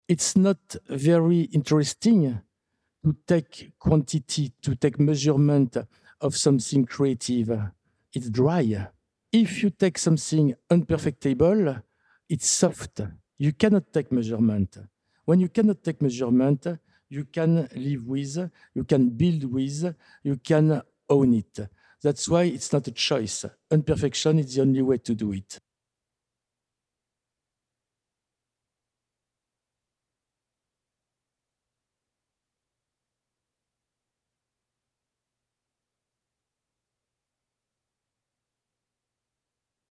Listen as they describe the artwork in their own words.